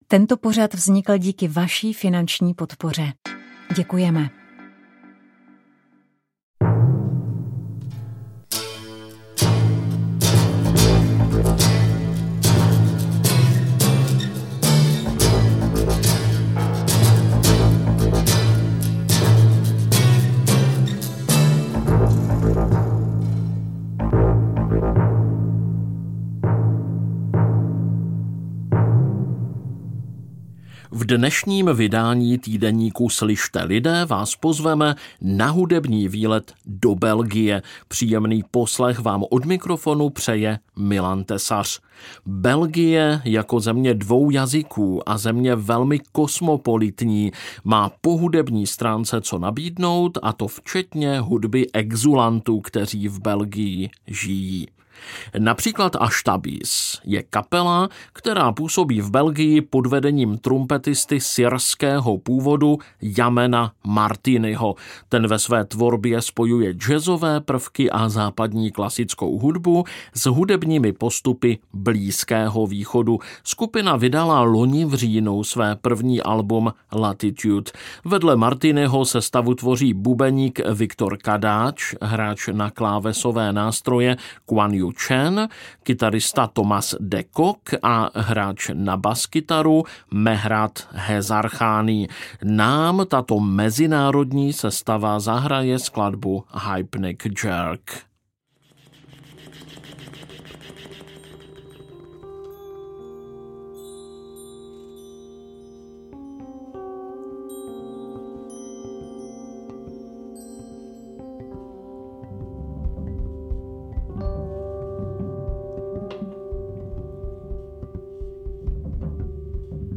Pod názvem Písně lesů, ohňů a cest uvedeme přehled nových hudebních alb z country, bluegrassu, akustického folku a trampské hudby.